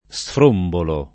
sfrombolo [ S fr 1 mbolo ]